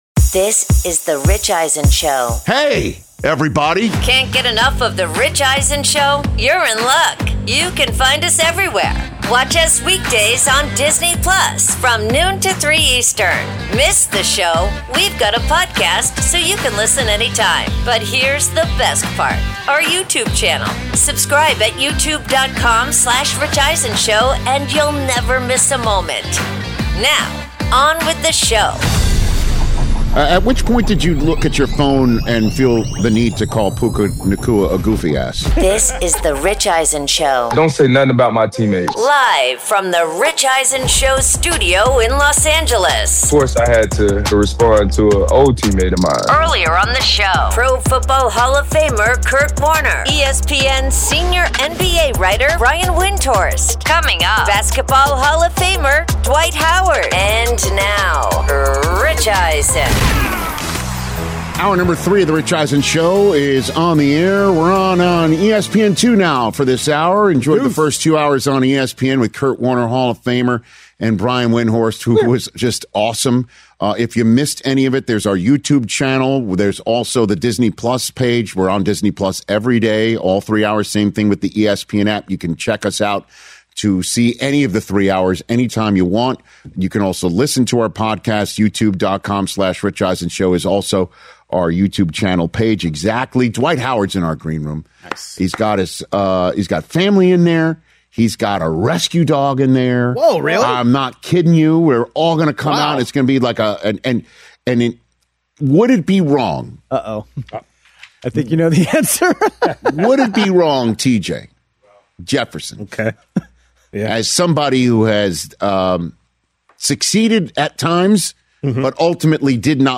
Hour 3: Final NFL Power Rankings, plus Basketball Hall of Famer Dwight Howard In-Studio